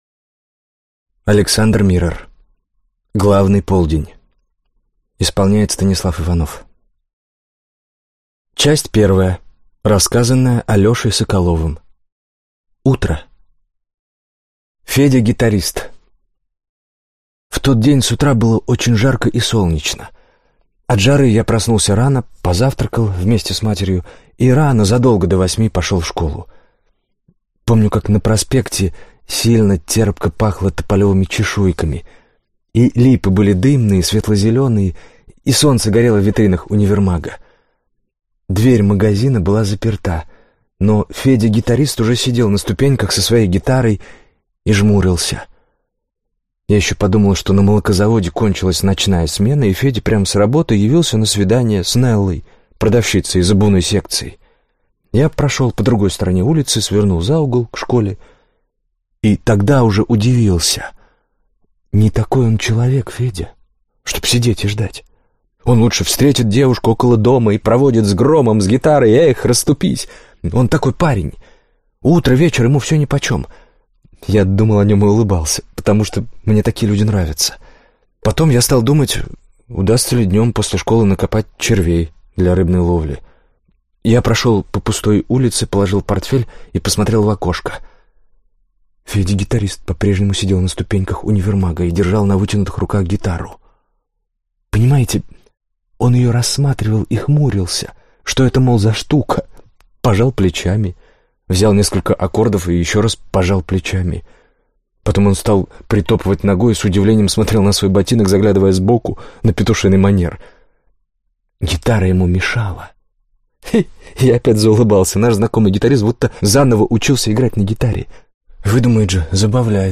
Аудиокнига Главный полдень. Дом скитальцев | Библиотека аудиокниг